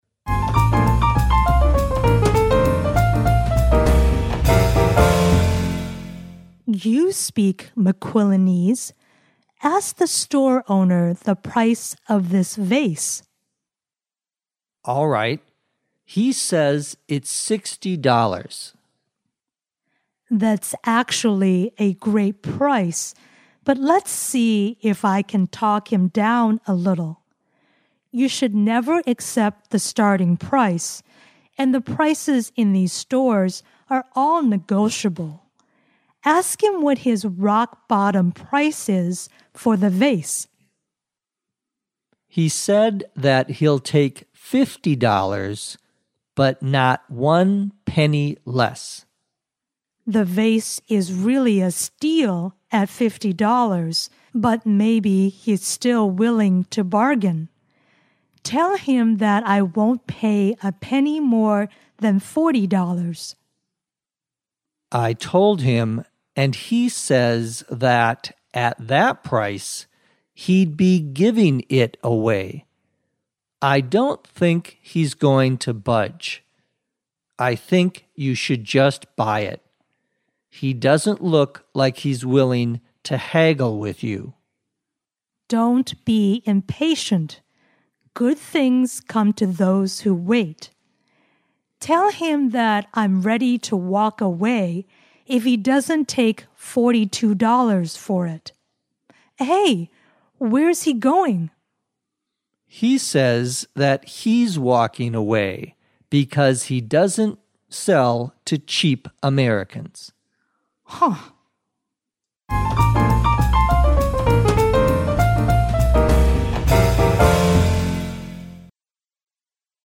地道美语听力练习:讨价还价